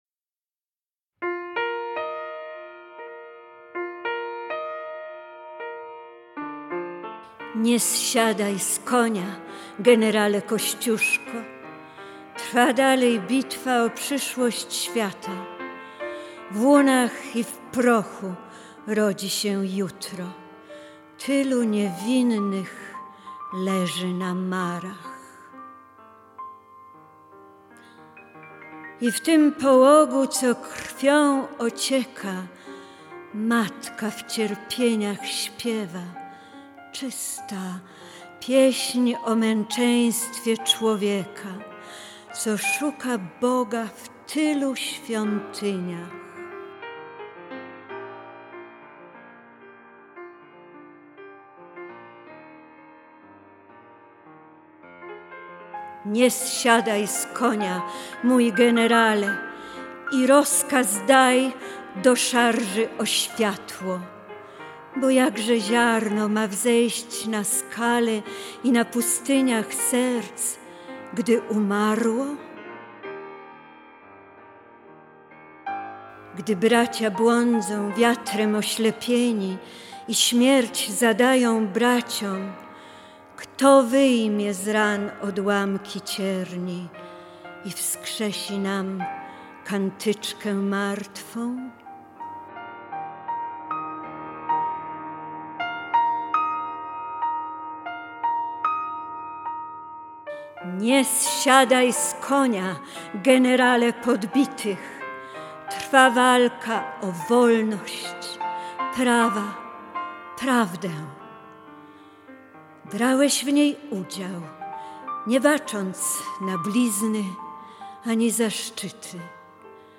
Nie zsiadaj z konia, Jenerale, recytuje